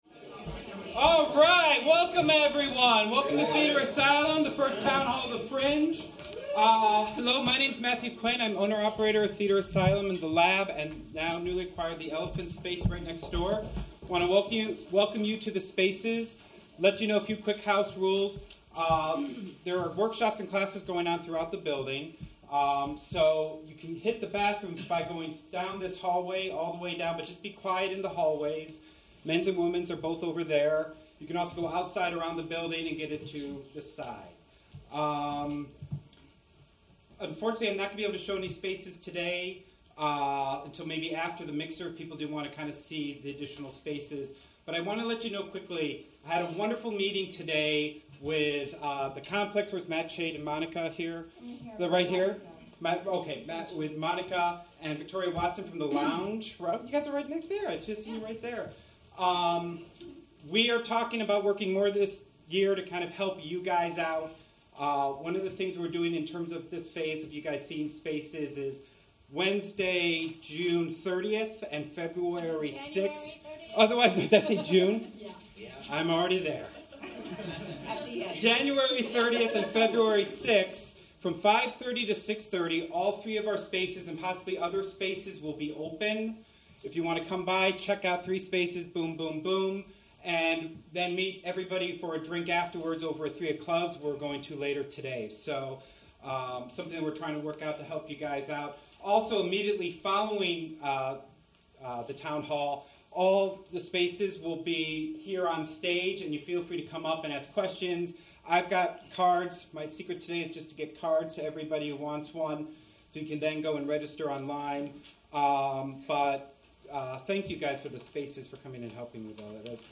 For those of you who missed it, we recorded the proceedings.